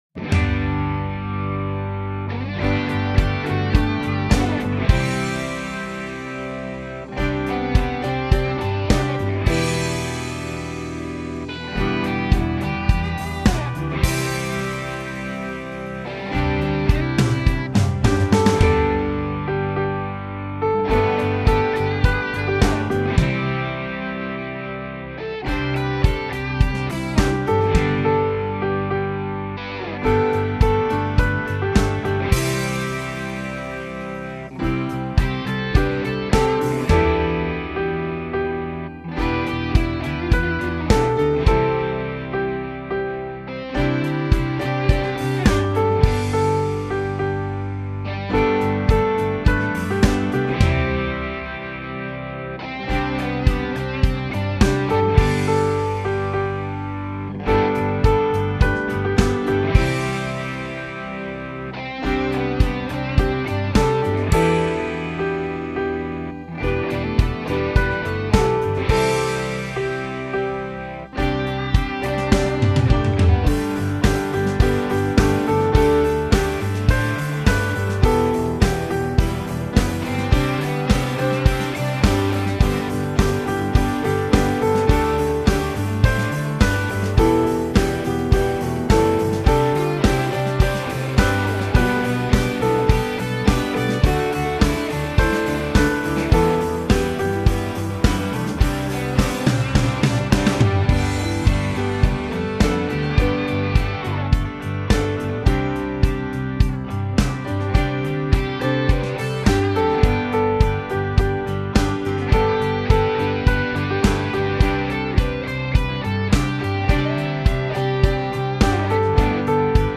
My backing is in G.